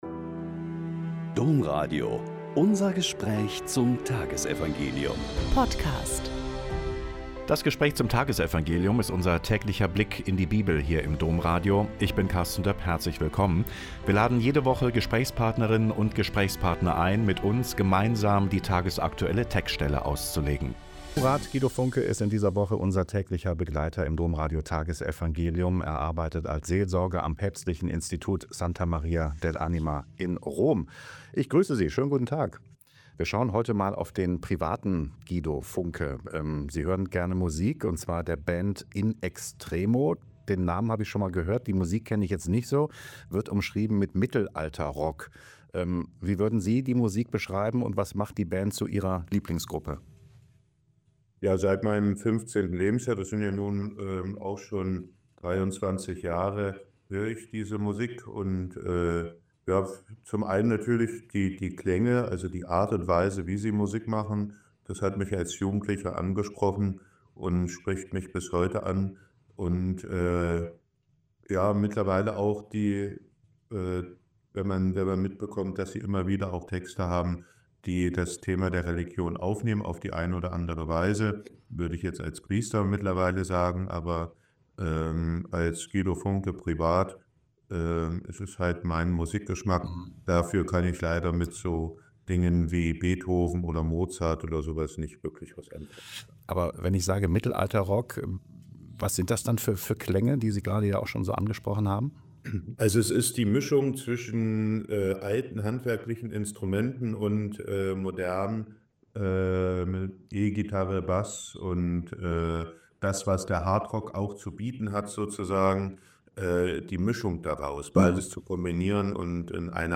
Mk 6,14-29 - Gespräch